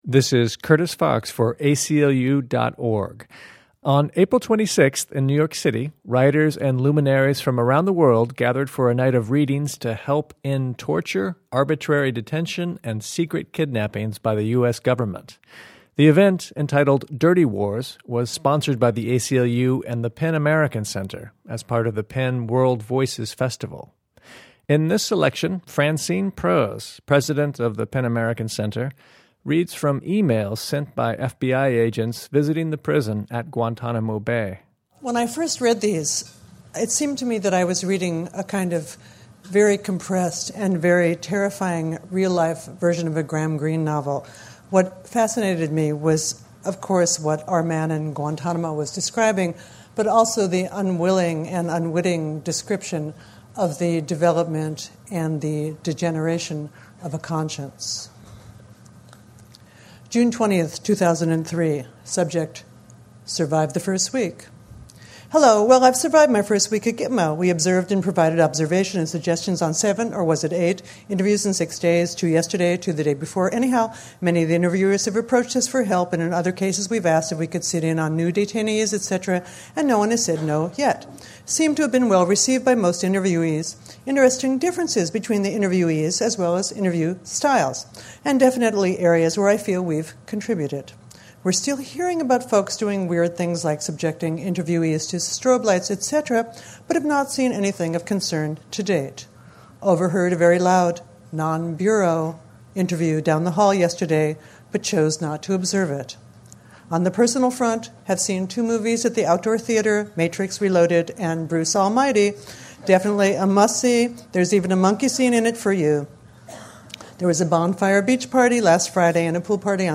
On April 26, 2007, PEN American Center and the ACLU co-sponsored "Dirty Wars," an evening of readings at Joe's Pub during the PEN World Voices Festival.